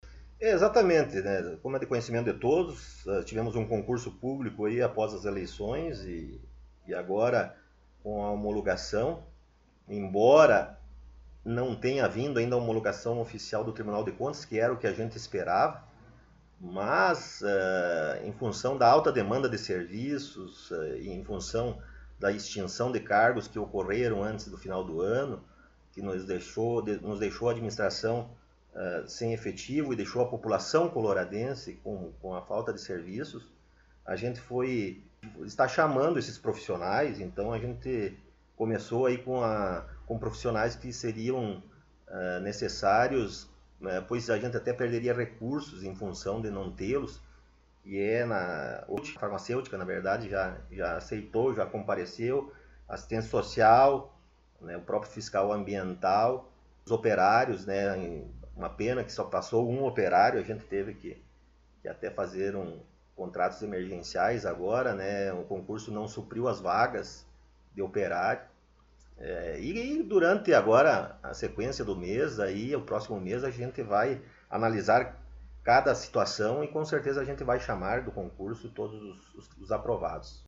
O Colorado em Foco esteve entrevistando na última sexta-feira ( 17 ), no gabinete da Prefeitura Municipal o prefeito Rodrigo Sartori.